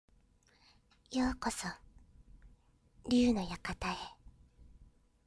？歳／女
■　Voice　■